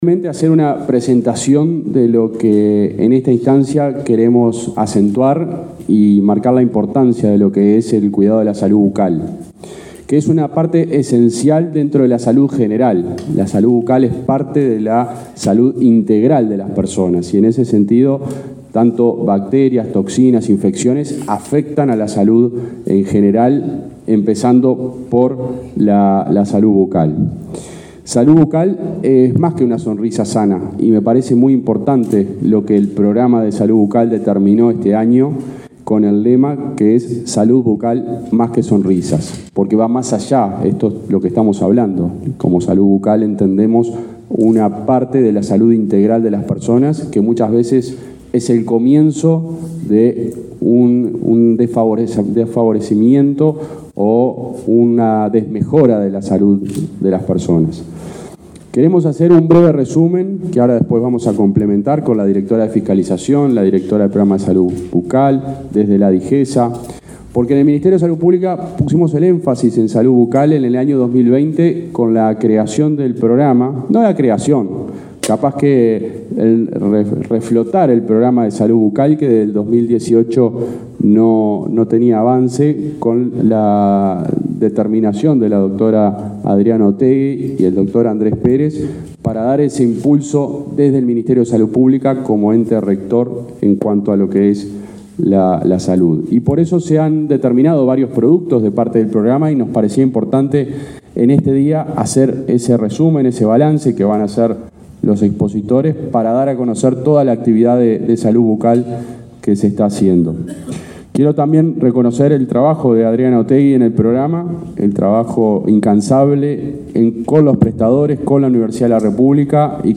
Palabras del subsecretario del MSP, José Luis Satdjian
Este martes 17, el subsecretario del Ministerio de Salud Pública (MSP), José Luis Satdjian, participó en el acto realizado en la sede de esa cartera,